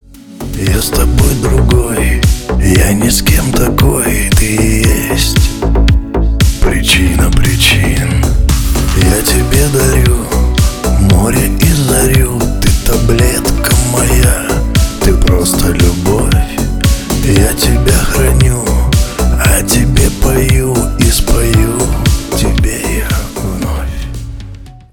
Шансон
спокойные